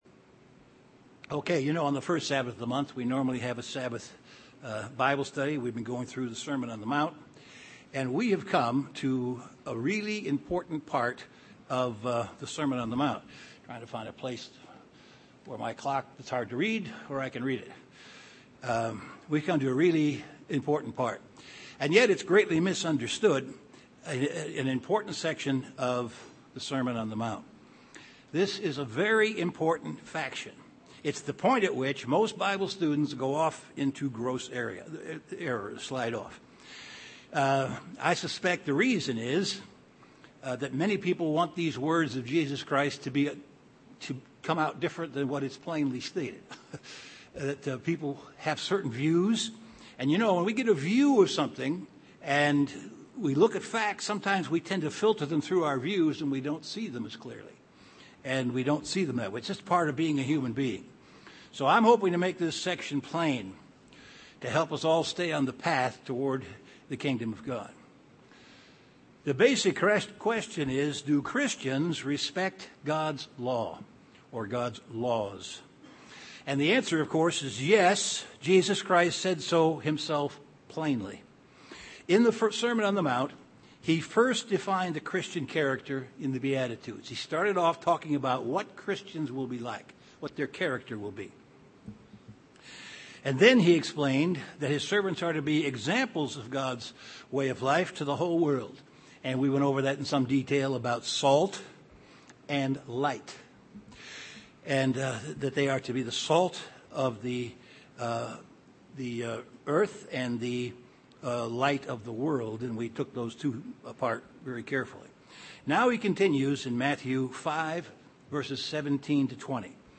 Given in Chicago, IL Beloit, WI
UCG Sermon Sermon on the Mount Studying the bible?